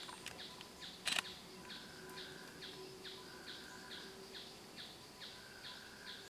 Planalto Tapaculo (Scytalopus pachecoi)
Life Stage: Adult
Location or protected area: Bio Reserva Karadya
Condition: Wild
Certainty: Recorded vocal
Churrin-plomiso.mp3